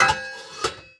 metal_lid_movement_impact_14.wav